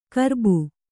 ♪ karbu